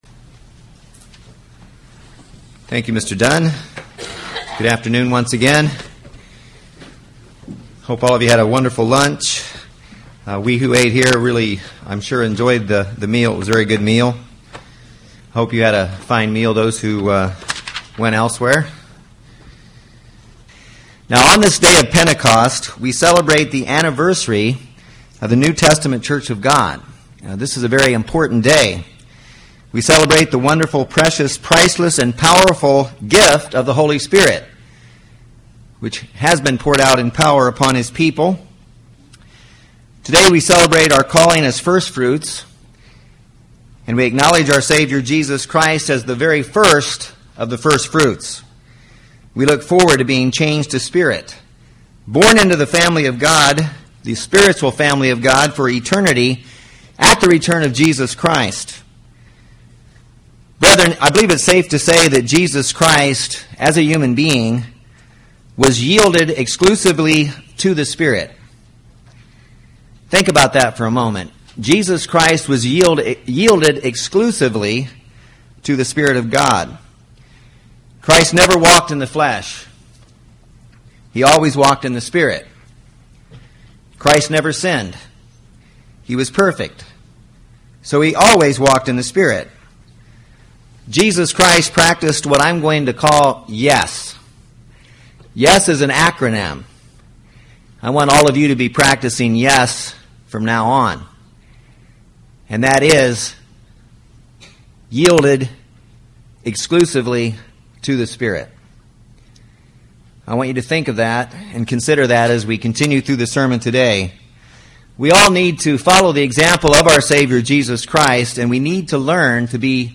This sermon gives five ways to learn to yield exclusively to the Spirit of God. Learn to say Y.E.S. to God and NO to sin.